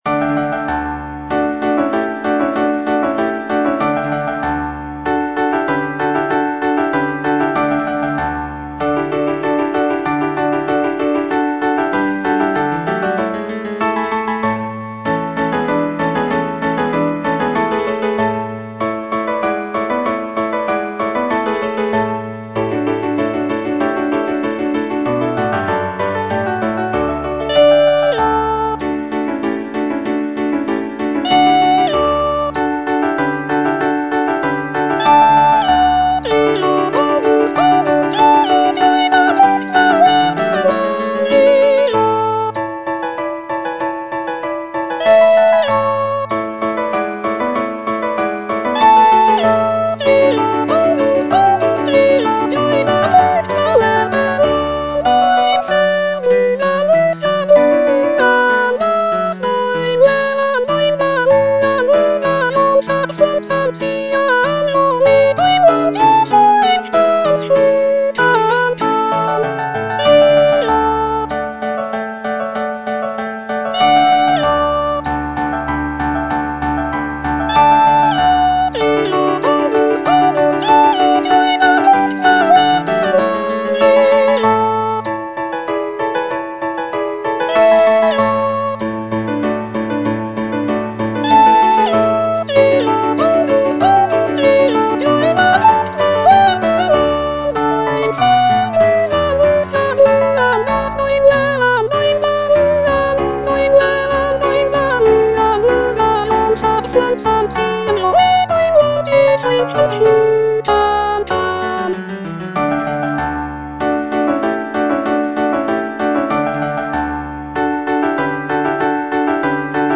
The cantata features a dialogue between the wind god Aeolus and the river god Alpheus, celebrating the prince's virtues and rulership. The music is lively and celebratory, with intricate counterpoint and virtuosic vocal lines.